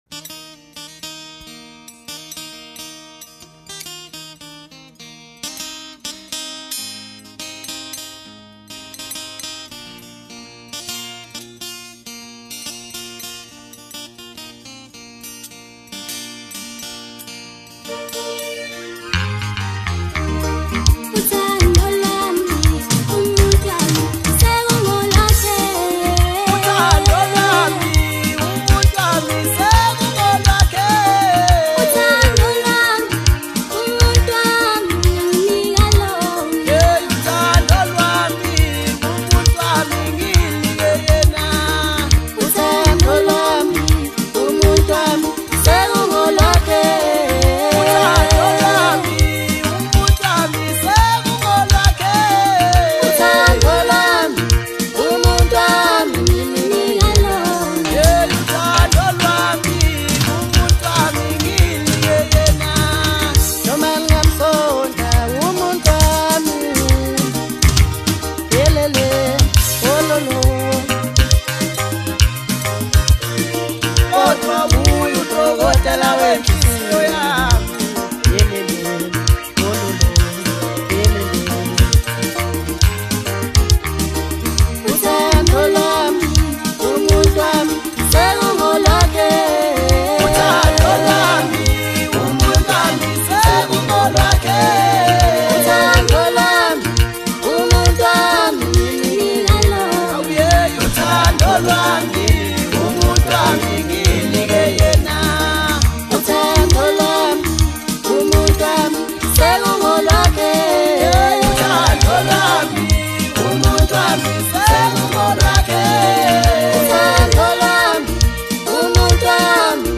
Amapiano, DJ Mix, Hip Hop